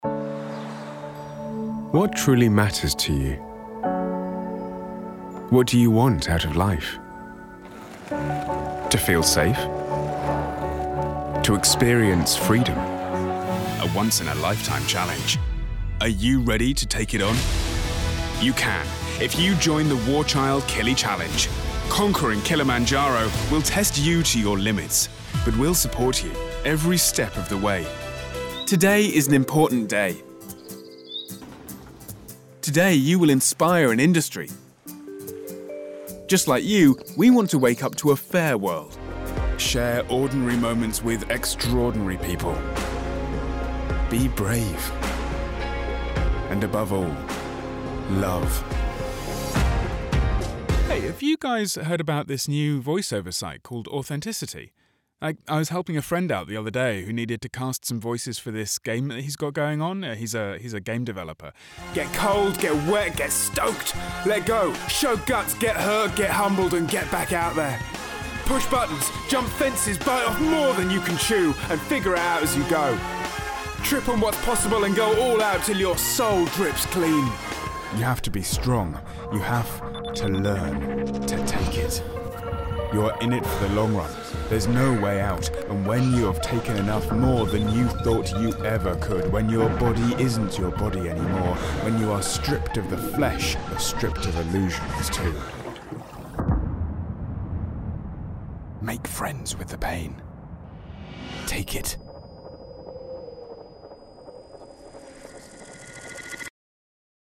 Engels (Brits)
Natuurlijk, Vertrouwd, Warm, Vriendelijk, Zakelijk
Commercieel